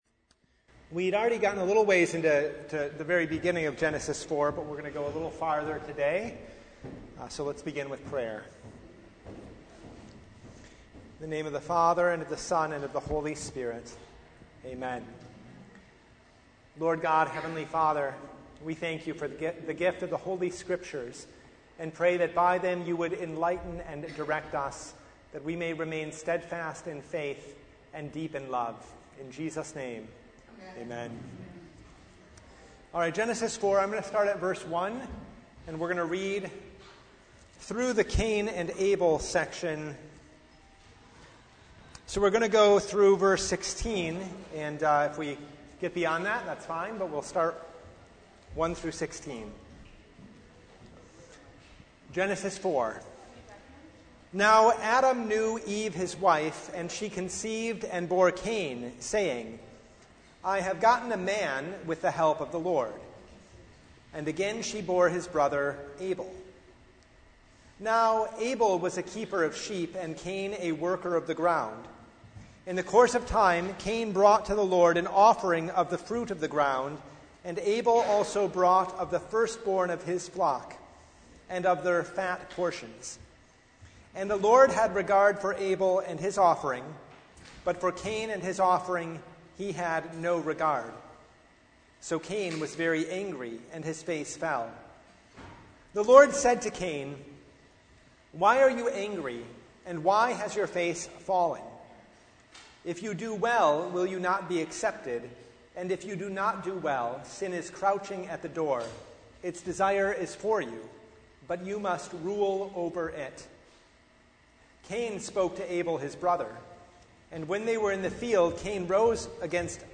Bible Study « A Sabbath to Remember The Fifth Sunday after the Epiphany